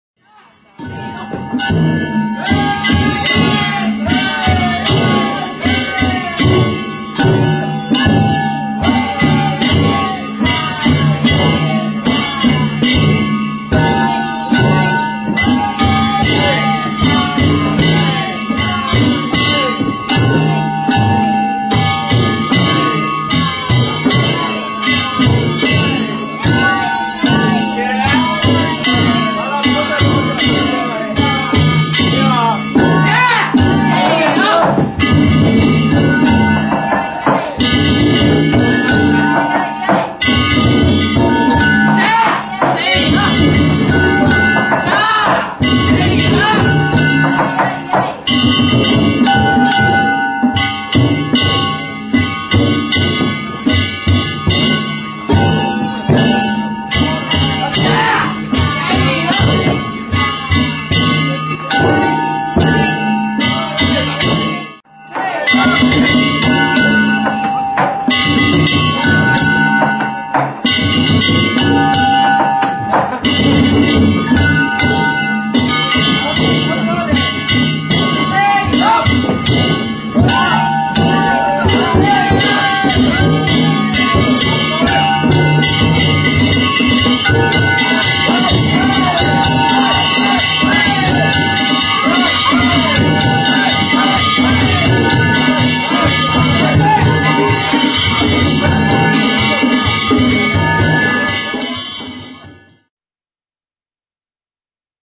平成２８年６月１１日、守口市の八番地車曳行を見に行ってきました。
囃子方が乗り込み、お囃子が始まりました。
二丁鉦と大太鼓は女性です。
曲がり角では八雲独特のお囃子です♪
ゆっくりとしたお囃子で進みます。
お囃子が激しくなり、速足で進みだしました。